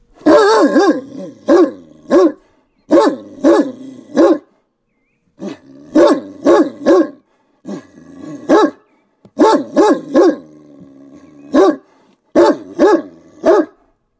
狗叫声
描述：一个盛大的danois在外面吠叫。有些鸟在背景中啁啾。
Tag: 吠叫 动物 树皮 宠物